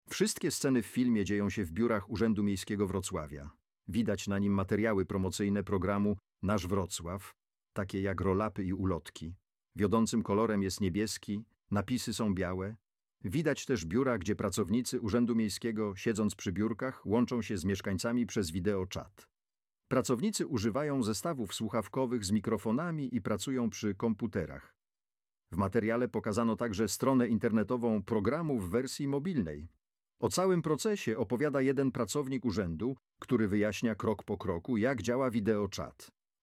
audiodeskrypcja-filmiku-załatwiaj-sprawy-w-programie-nasz-wrocław-przez-czat-wideo_1.mp3